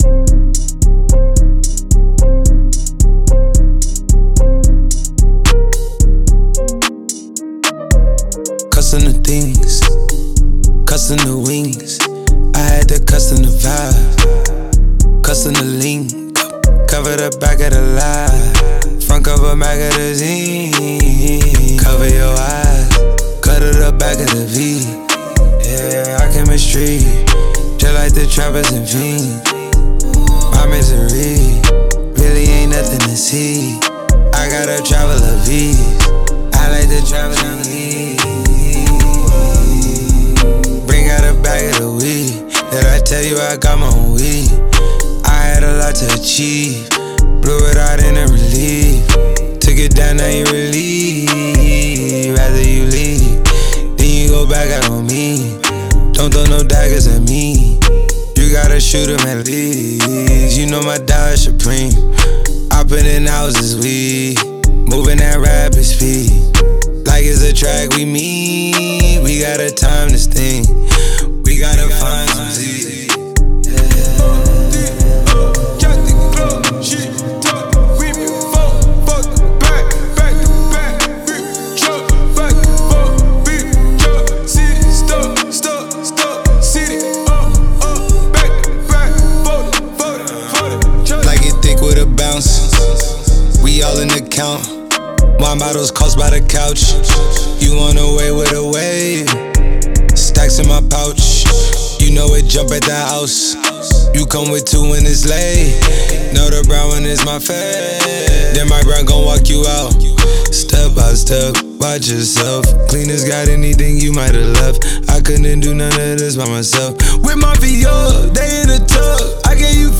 А так-то, у вас бас не работал в треке.